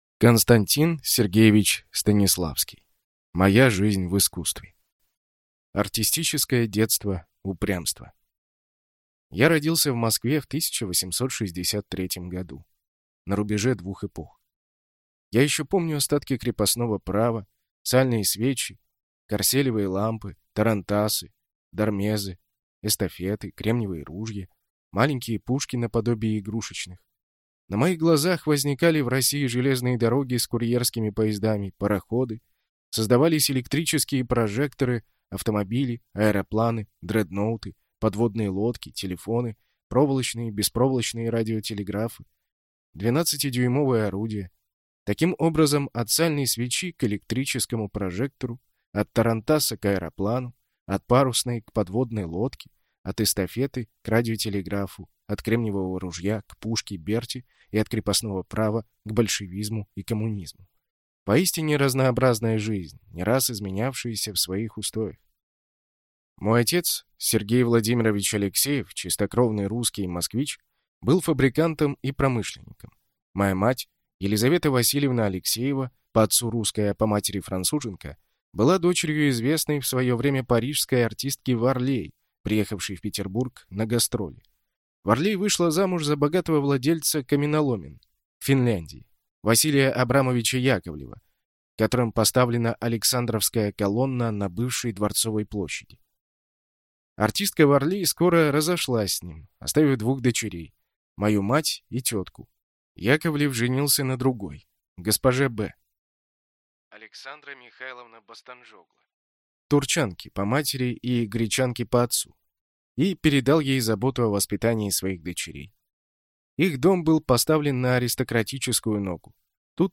Аудиокнига Моя жизнь в искусстве | Библиотека аудиокниг